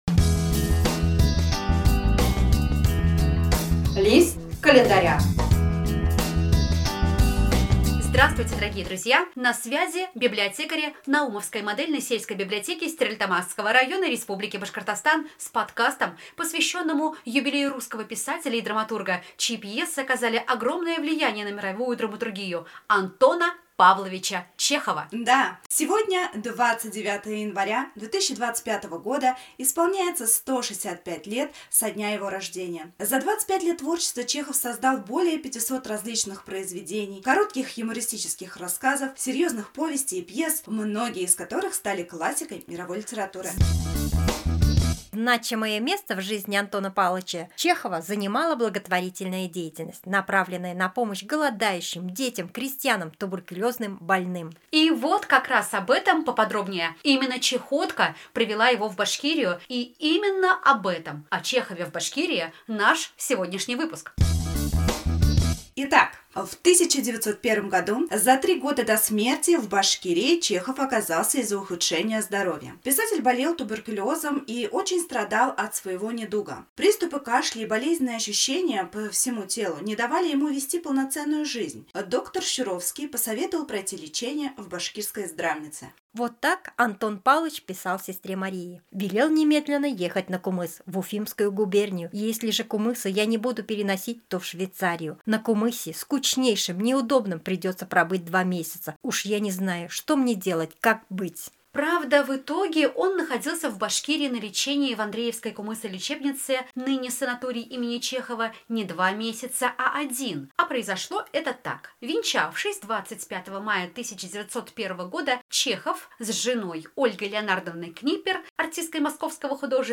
Студийцы звукозаписи “Ля-мажор” и библиотекари Наумовской модельной сельской библиотеки подготовили подкаст, посвященный юбилею автора более 500 различных произведений.